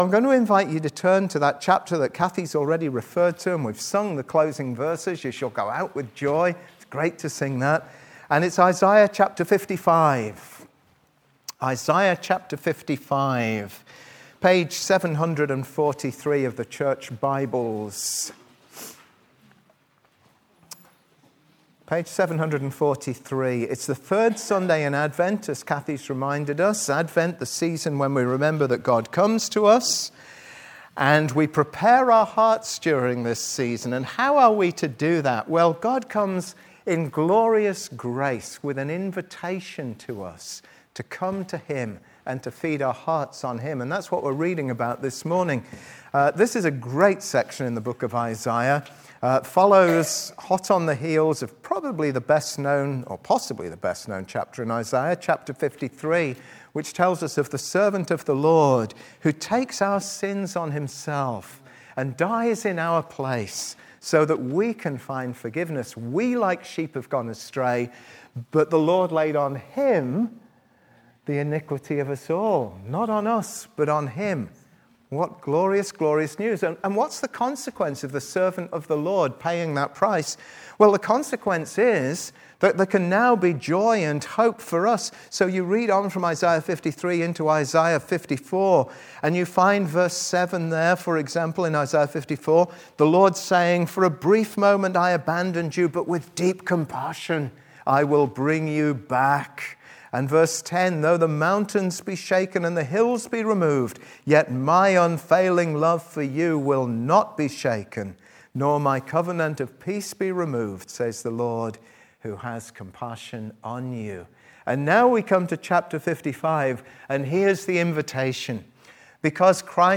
Service Type: Sunday 11:15